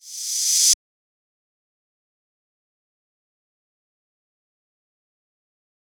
Crash [7].wav